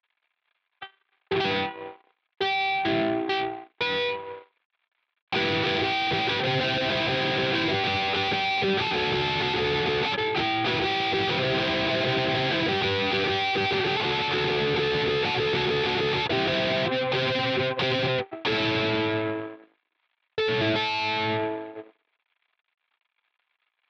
ukulelemetal.mp3